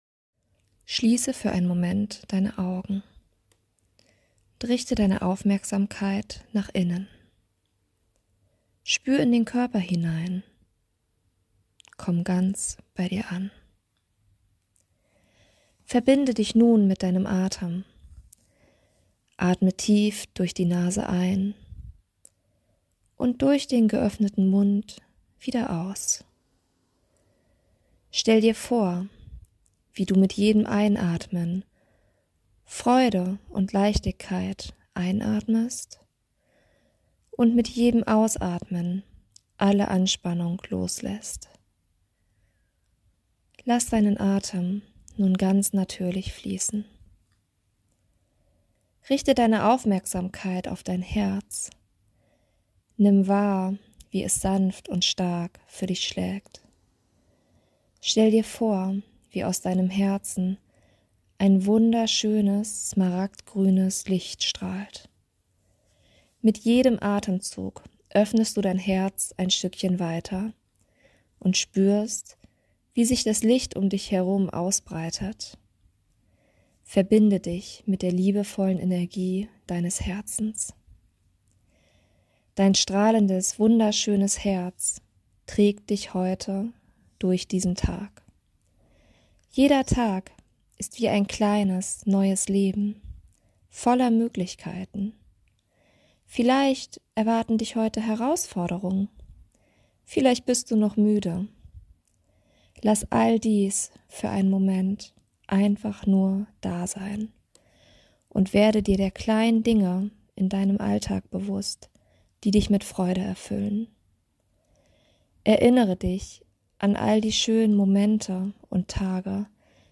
Morgenmeditation.mp3